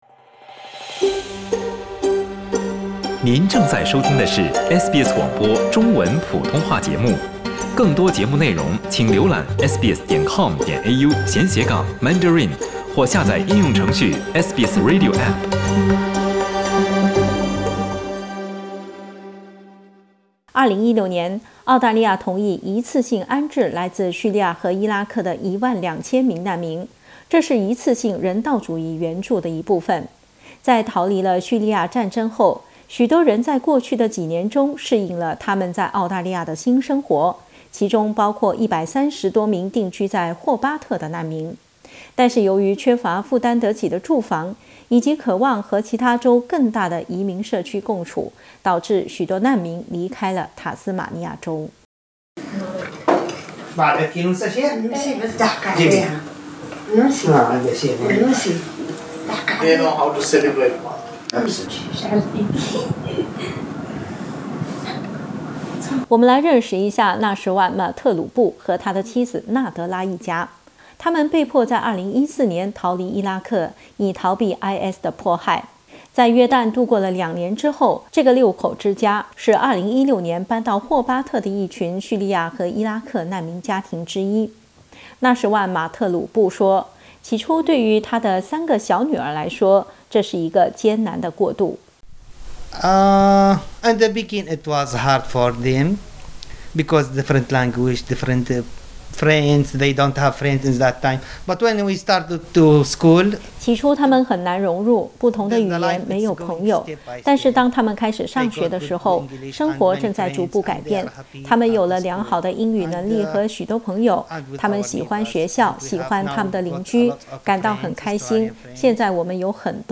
由于缺乏负担得起的住房，渴望与其他州更广大的移民社区共处，许多难民离开了塔斯马尼亚州。 点击图片收听详细报道。